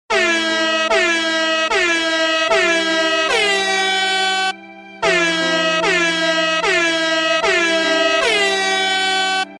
Air Horn Sad Violin Corto